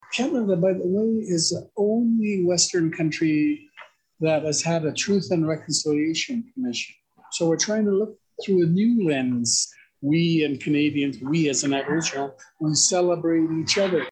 In a video, Indigenous Chief Robert Joseph said that when he first heard the Truth and Reconciliation Commission report his “eyes were flowing with tears.”